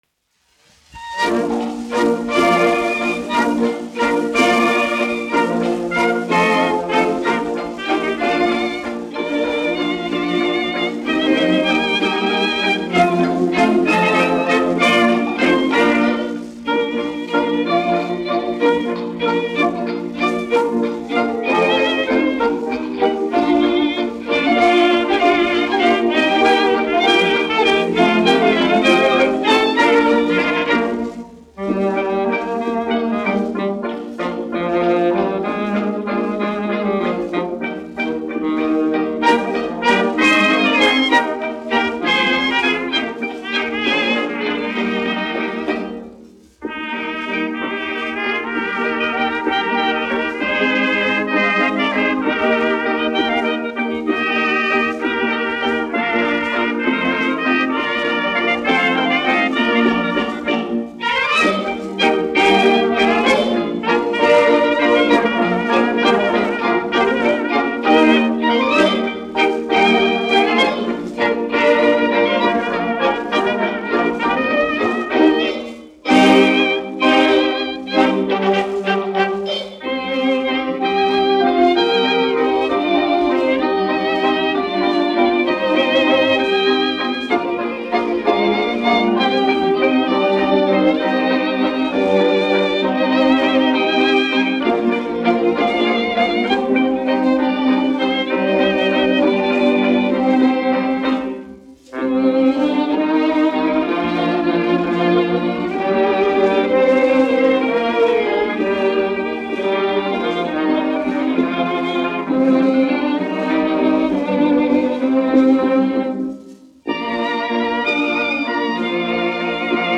1 skpl. : analogs, 78 apgr/min, mono ; 25 cm
Valši
Orķestra mūzika
Tautasdziesmas, latviešu--Instrumentāli pārlikumi
Skaņuplate